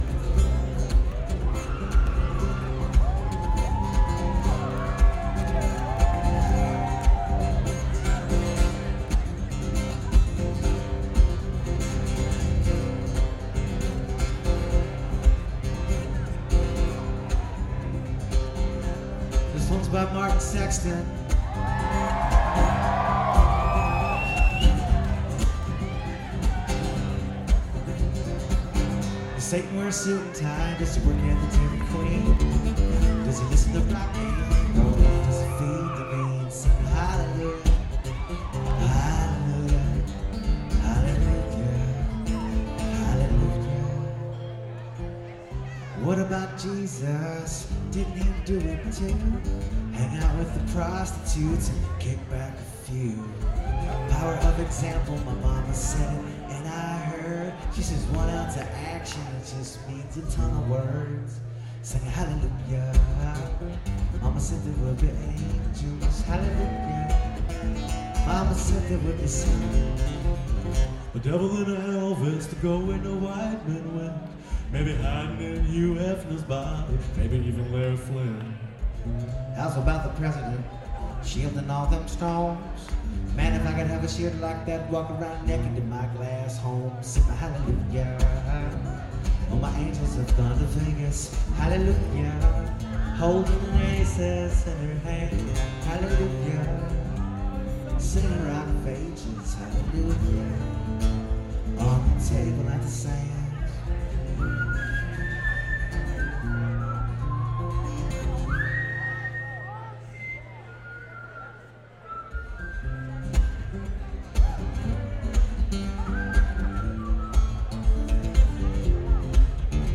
from a 2007 show at Lupo’s in Providence
mostly gentle treatment